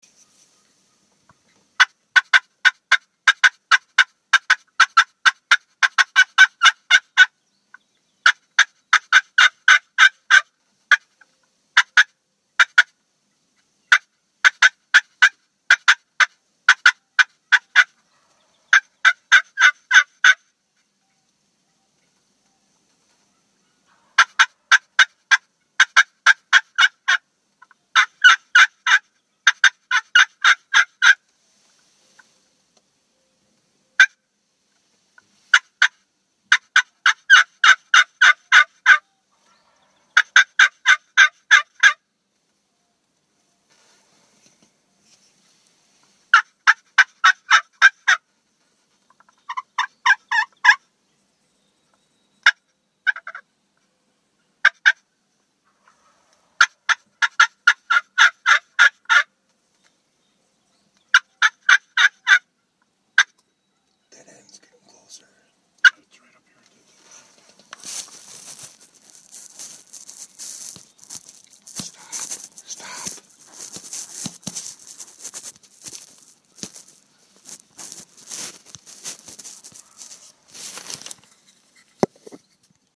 Willow Ridge Tongue Teaser Turkey Call Recorded Sounds
The sound files below were recorded outside during hunting situations.
Sound volume is much better.